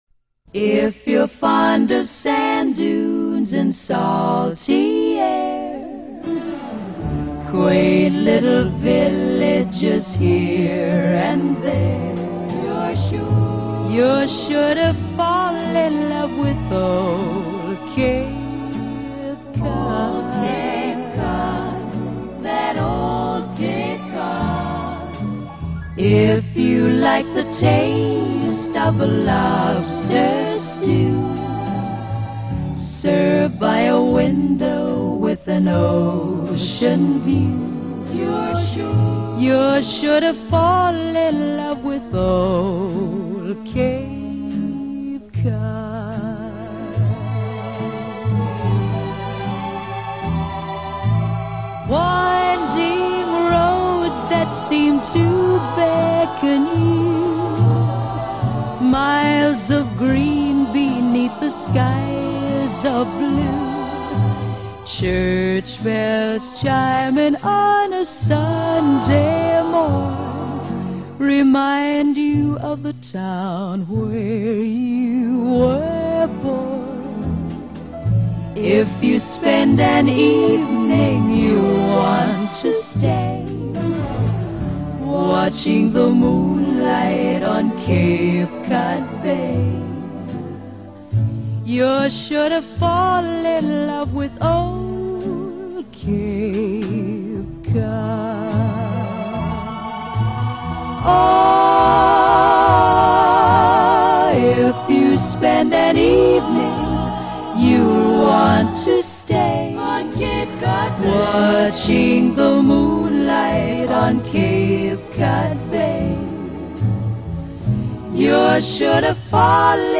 Tags1950s 1957 pop Southern US winter